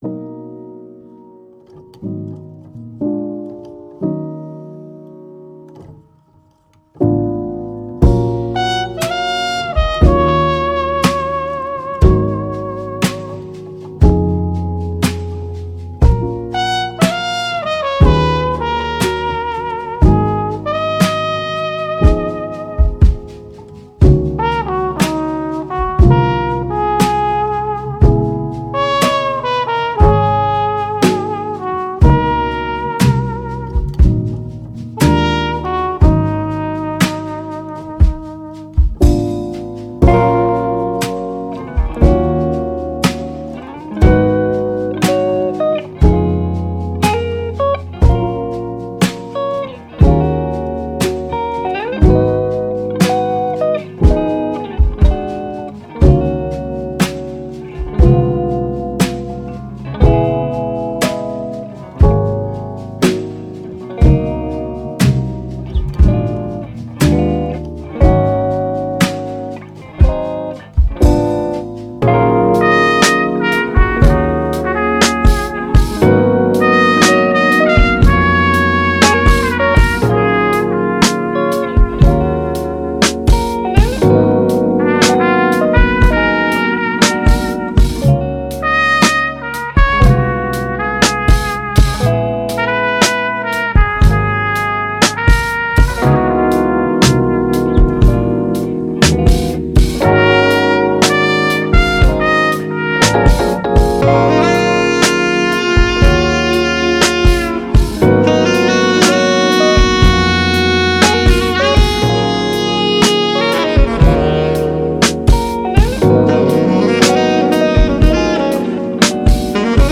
Jazz, Chilled, Vocal, Thoughtful, Smokey, Slow